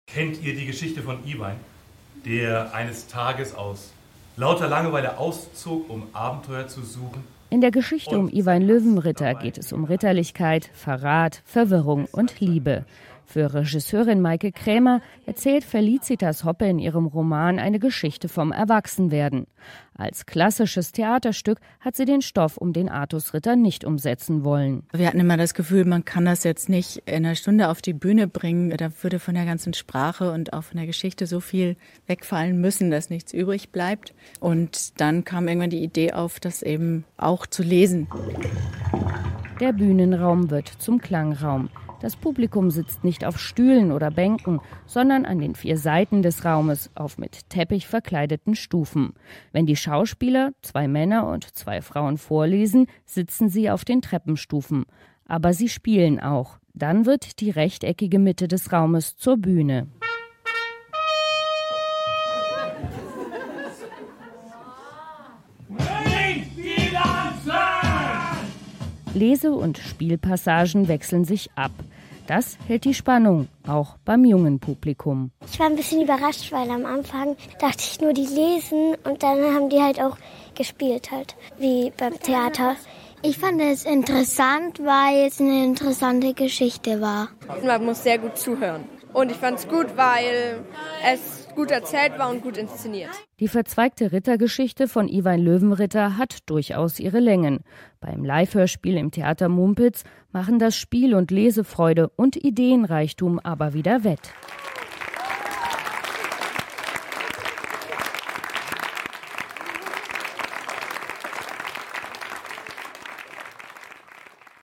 (Nürnberger Nachrichten) Die ausführlichen Kritiken gibt es hier, einen Radiobeitrag